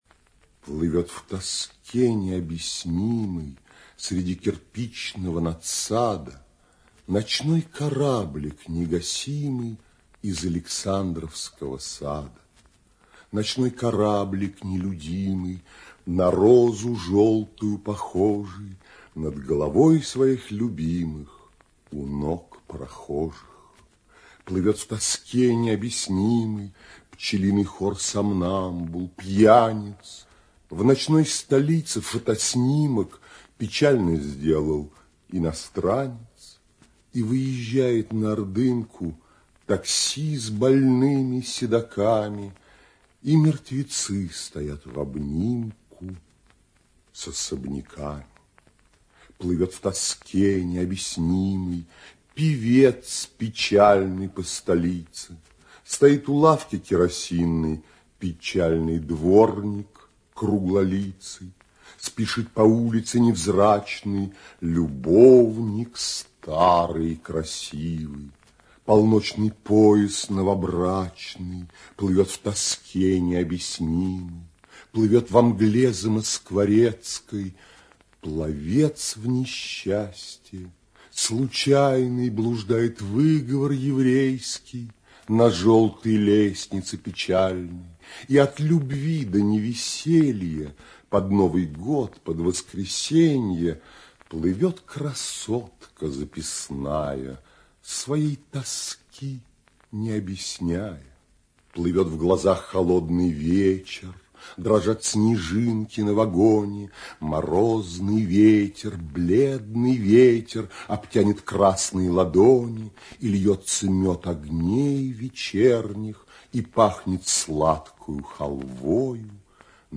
В 70-80 гг. на эстраде Козаков обычно выступал без музыкального сопровождения, но в записях музыка играет всё большую роль.